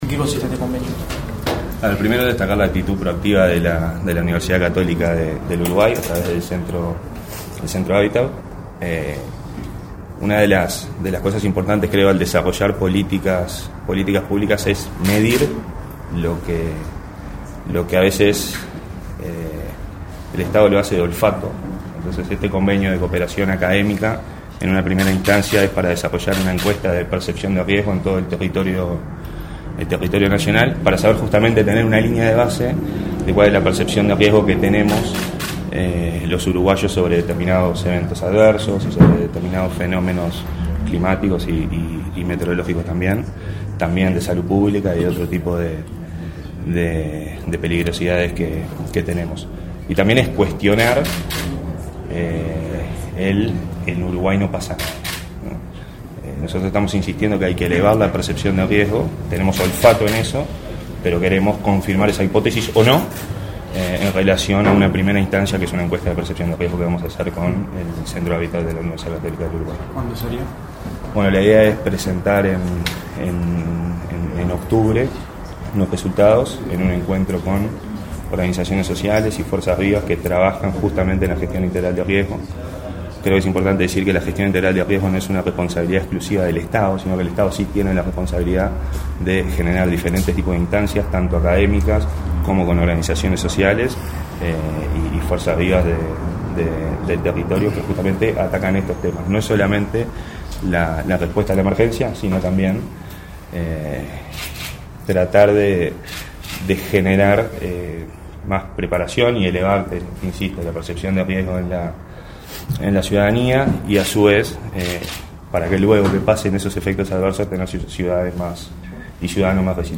Declaraciones a la prensa del director del Sinae, Santiago Caramés
Tras el evento, el director del Sinae realizó declaraciones a la prensa.